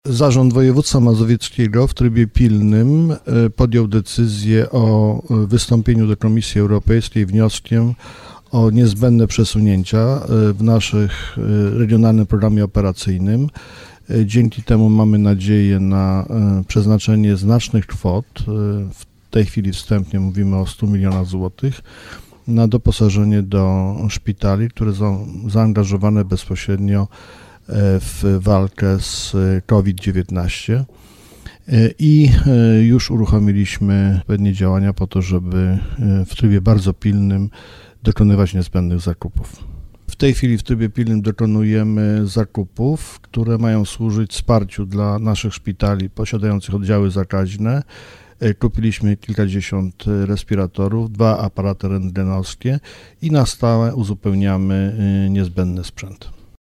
– W trybie pilnym wystąpiliśmy do Komisji Europejskiej o przesunięcia w regionalnym programie operacyjnym. Dzięki temu mamy nadzieję na przeznaczenie znacznych kwot na doposażenie szpitali, które zaangażowane są w walkę z COVID-19 – tłumaczy marszałek Adam Struzik.